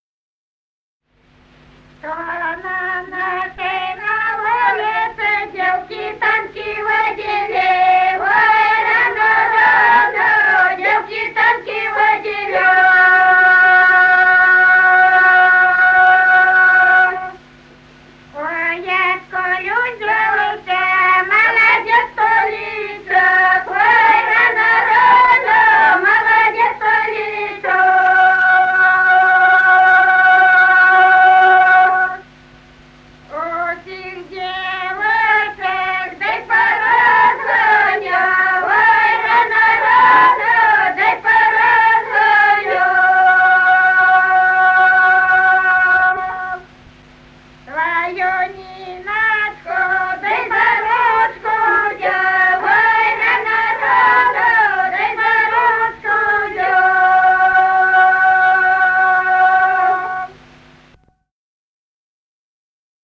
Народные песни Стародубского района «Что на нашей на улице», духовская таночная.